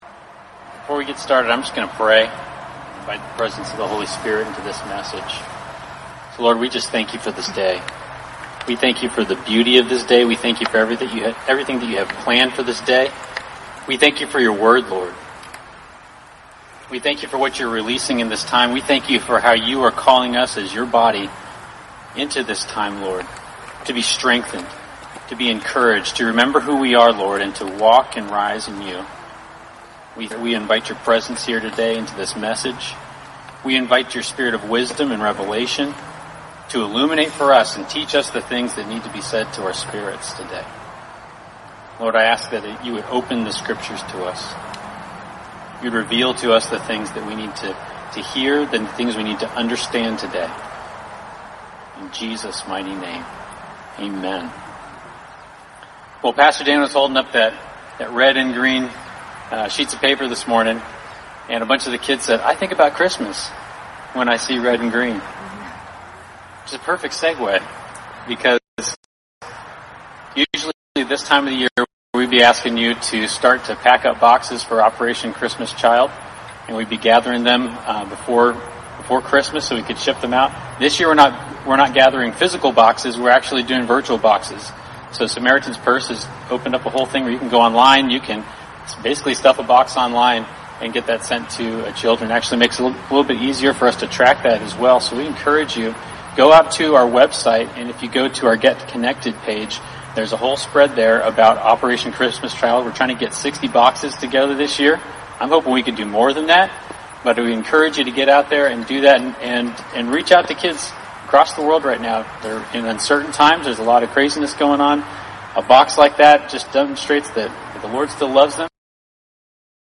(Due to internet dropouts there are numerous skips in the audio and video.)